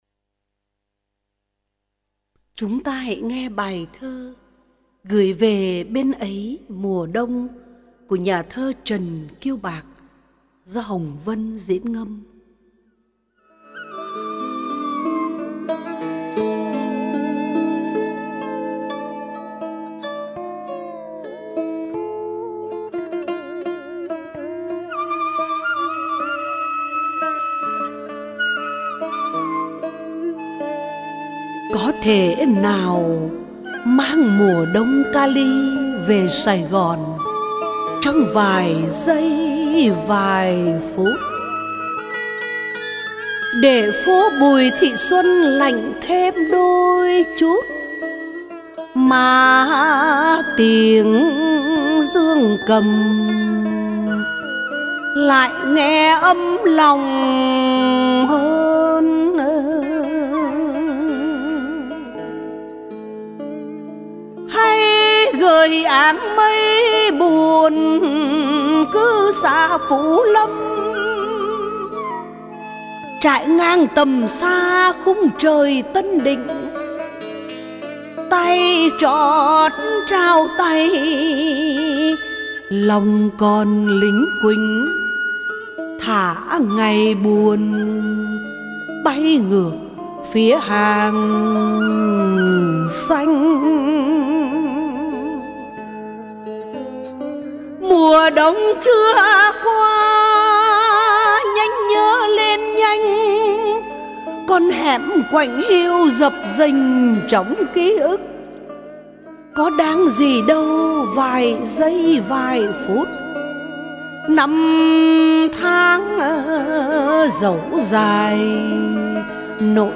Ngâm Thơ